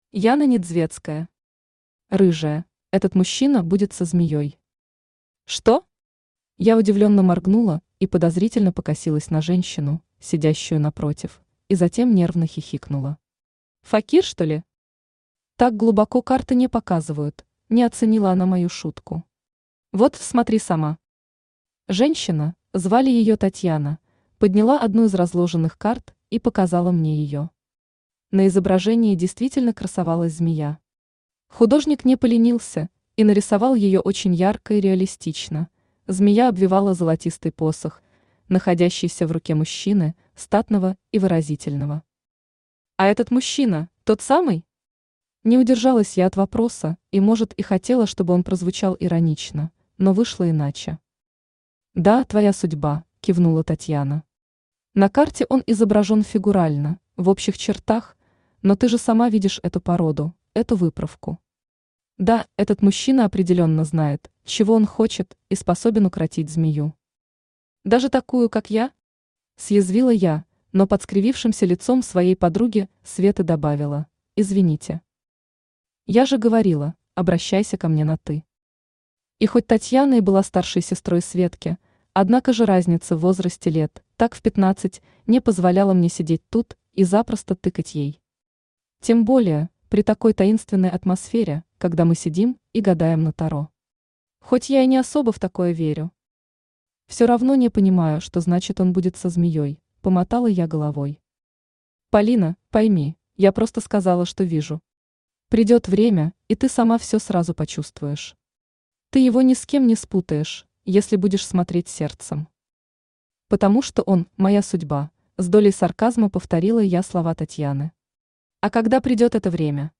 Аудиокнига Рыжая | Библиотека аудиокниг
Aудиокнига Рыжая Автор Яна Сергеевна Недзвецкая Читает аудиокнигу Авточтец ЛитРес.